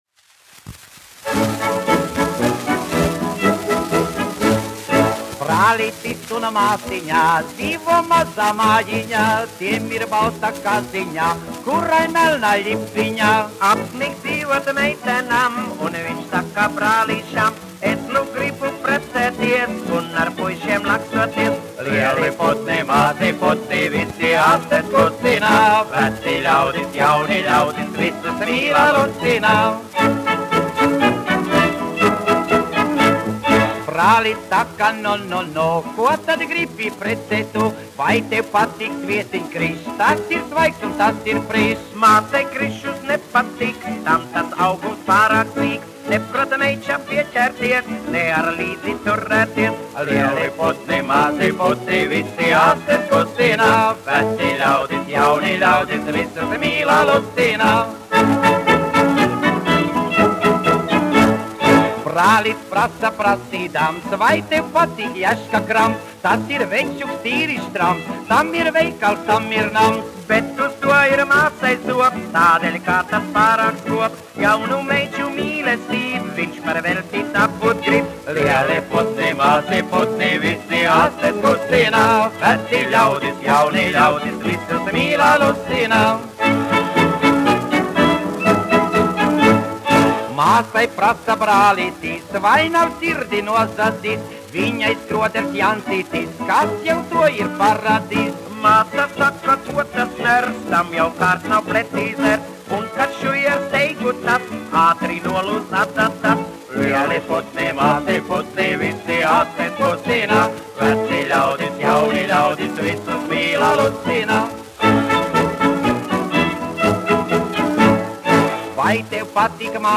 1 skpl. : analogs, 78 apgr/min, mono ; 25 cm
Populārā mūzika -- Latvija
Fokstroti
Skaņuplate